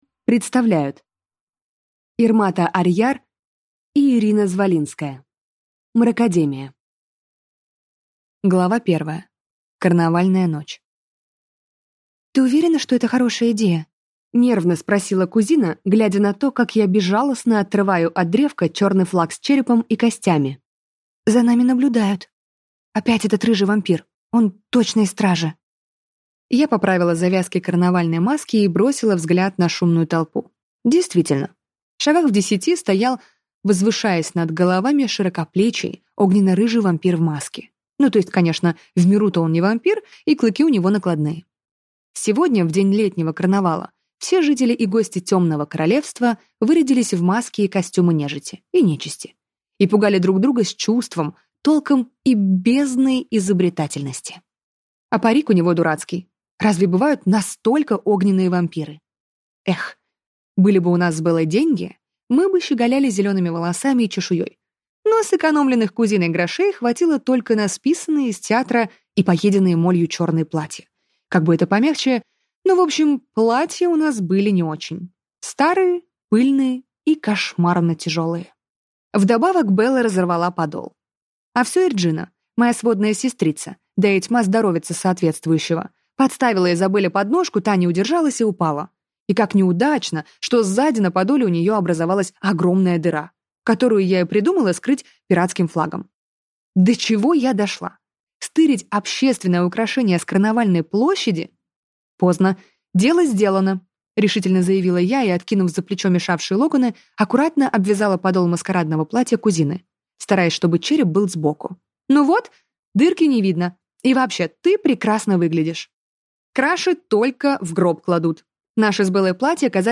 Аудиокнига Мракадемия | Библиотека аудиокниг